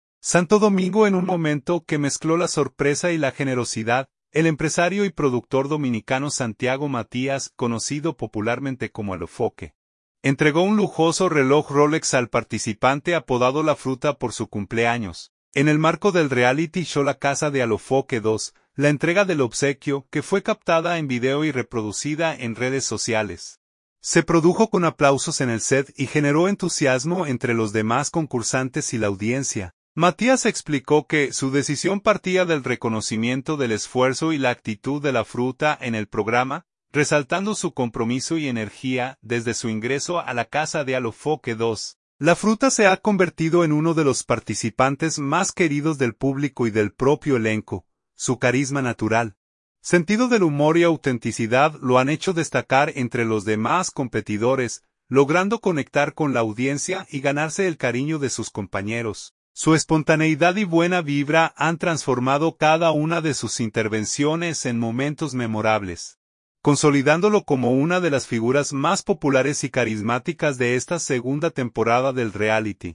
La entrega del obsequio, que fue captada en video y reproducida en redes sociales, se produjo con aplausos en el set y generó entusiasmo entre los demás concursantes y la audiencia.